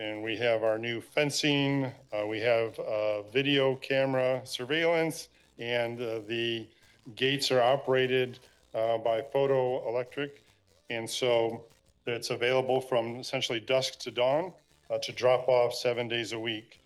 Coldwater City Manager Keith Baker said during Monday’s City Council meeting that brush, leaves and yard waste can continue to be dropped off by Coldwater City and Township residents at the City’s recently improved Municipal Services facility on Industrial Avenue.